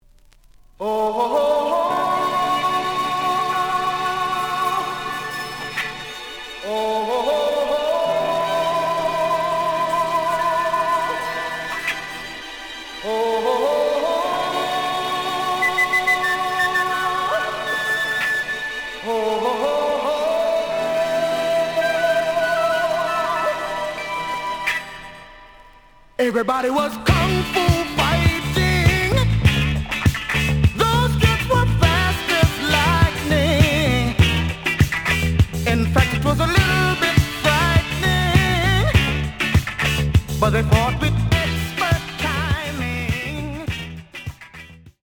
The audio sample is recorded from the actual item.
●Genre: Disco
Slight noise on beginning of A side, but almost good.